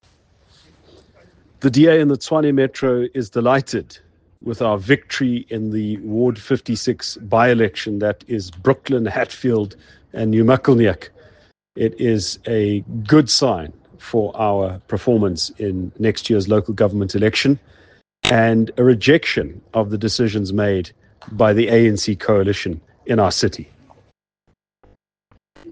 Note to Editors: Please find English and Afrikaans soundbites by Ald Cilliers Brink